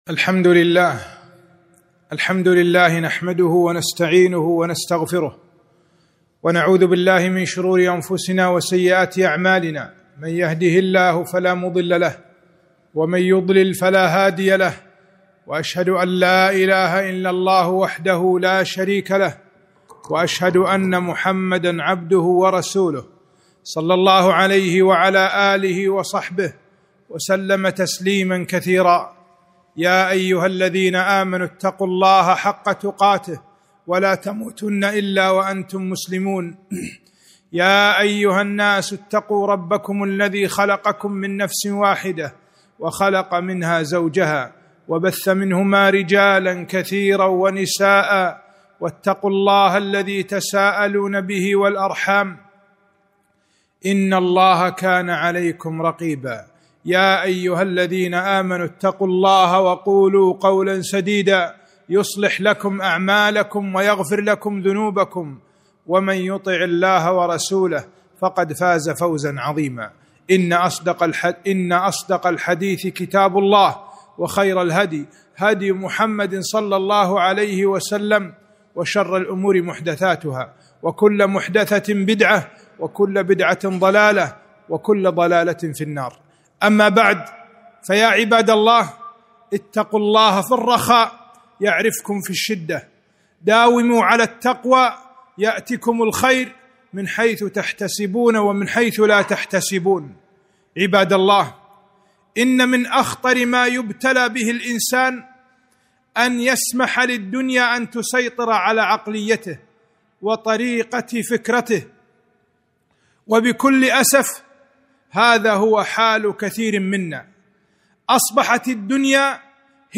خطبة - العافية